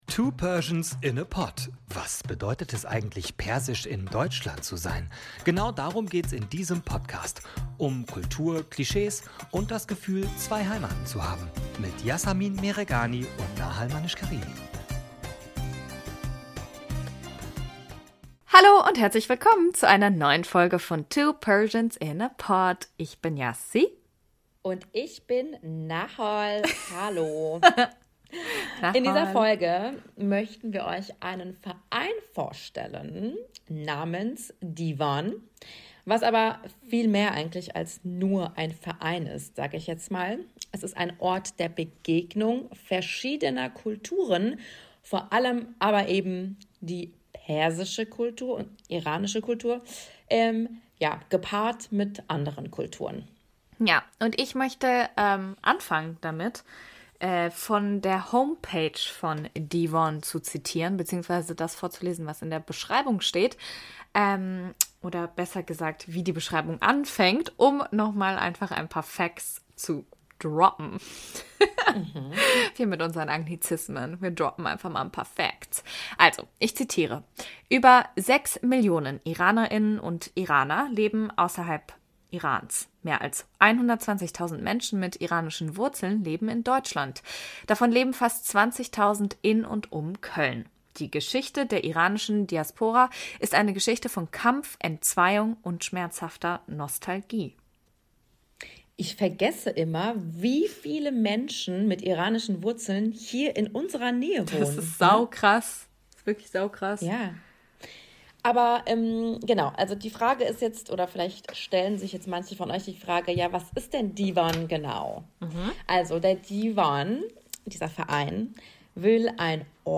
Und es wird sehr sehr viel gelacht in dieser Folge.